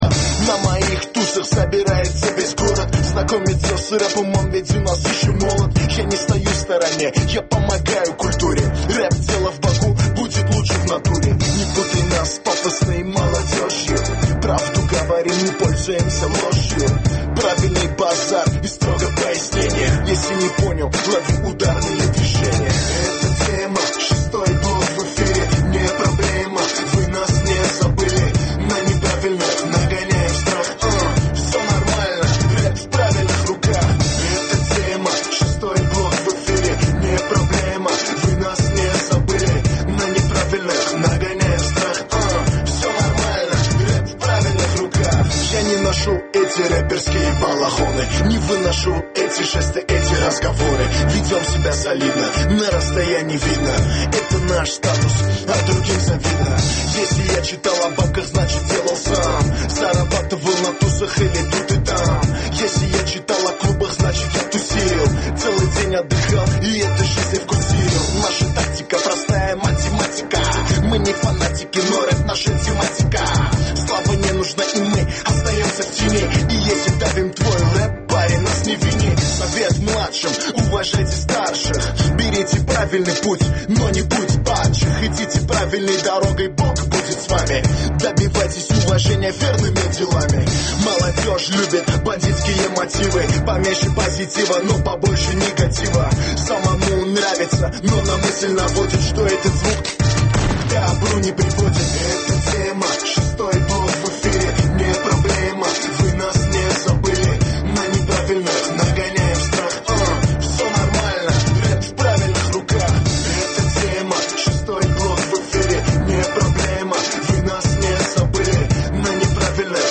Gənclərin musiqi verilişi